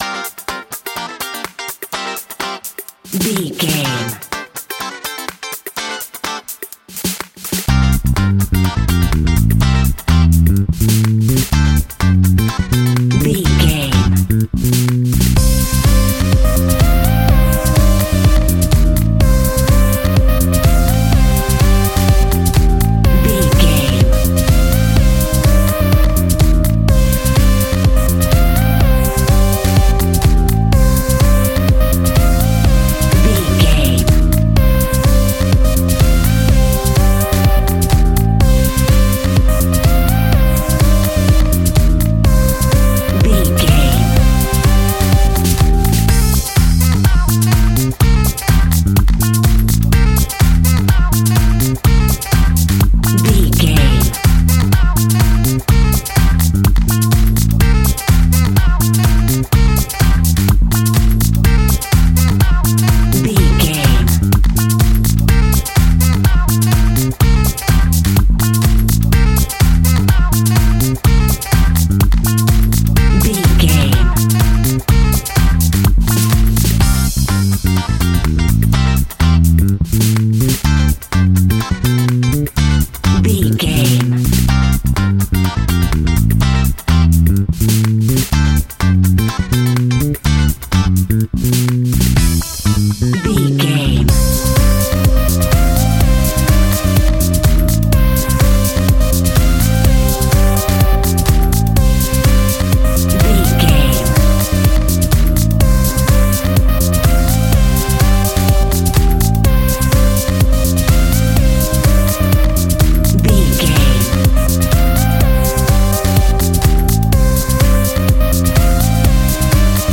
Aeolian/Minor
funky
groovy
uplifting
driving
energetic
bass guitar
electric piano
drums
synthesiser
electric guitar
funky house
disco house
electronic funk
upbeat
clavinet
horns